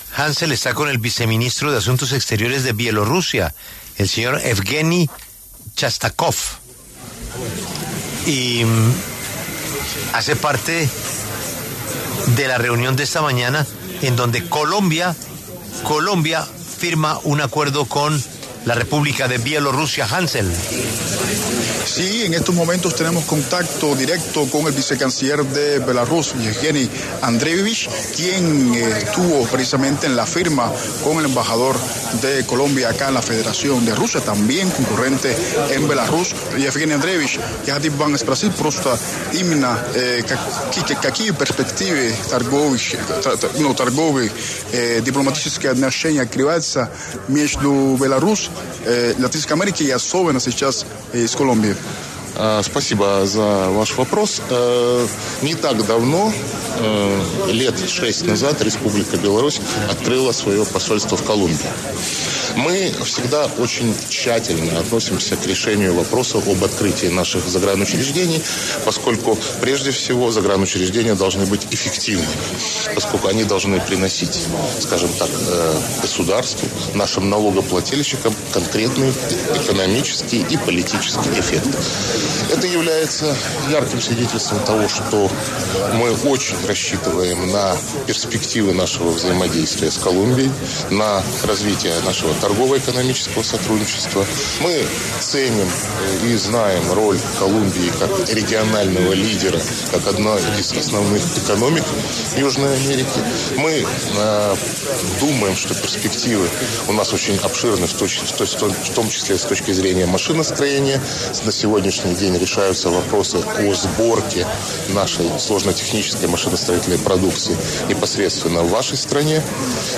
Asimismo, La W conversó con el vicecanciller Shestakov, quien celebró la noticia, augurando fortalecimiento comercial entre ambas naciones.